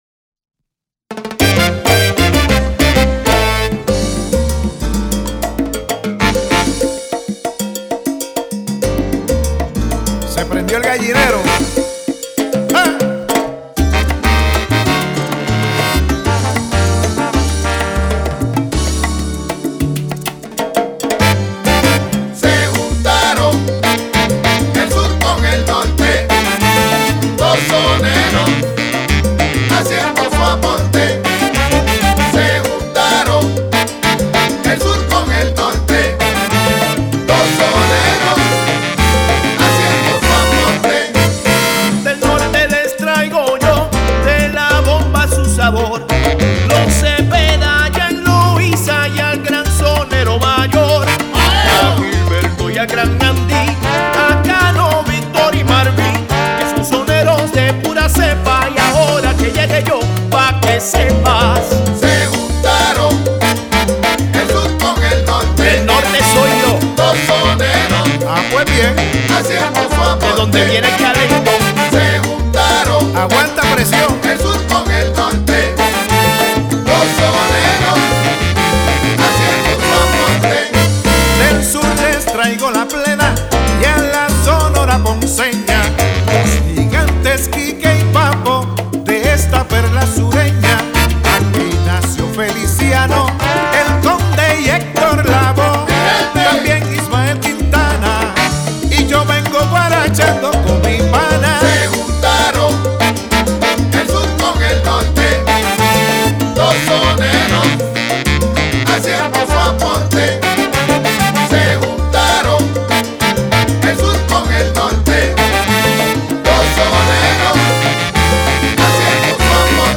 Piano y Teclados
Trombón
Trompeta
Bajo
Percusión
Barítono